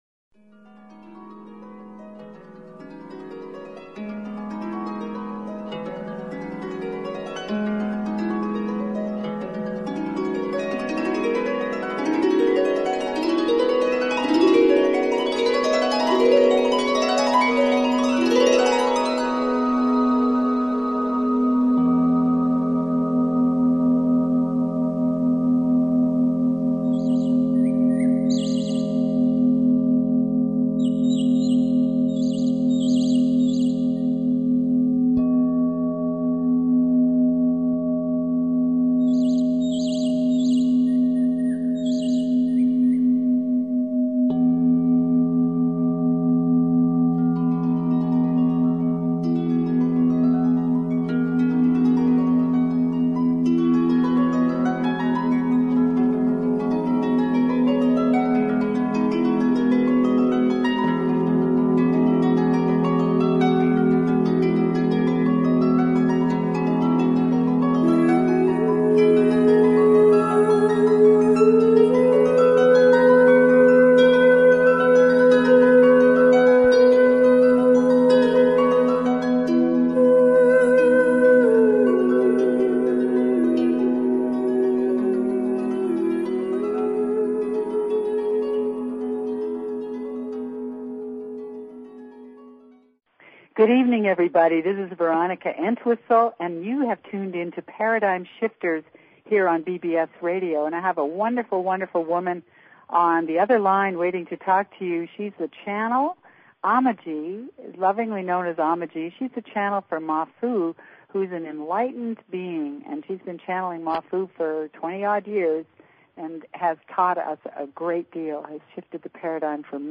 Talk Show Episode
This is an inspiring and powerful interview.